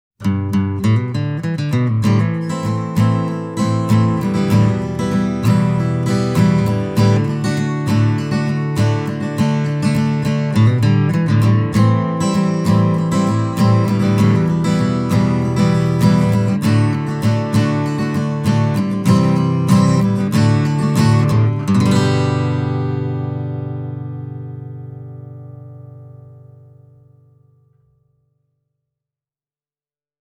There’s not a lot of deep bass, the mid-range has a certain boxy quality, and the whole is rounded off by a healthy dose of chiming top end.
But a small body doesn’t necessarily mean a puny sound – this Farida is a nice little barker when played with a plectrum.